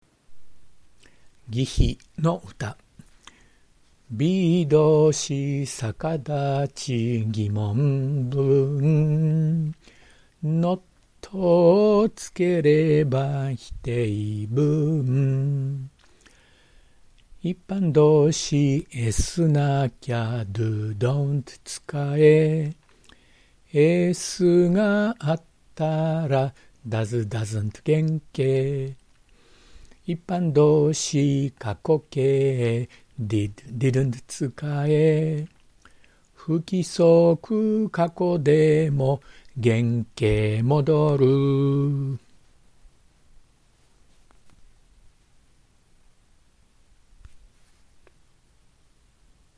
まとめに、全部を覚えやすいように作った替え歌を歌いました。「海」の旋律で「疑・否の歌」。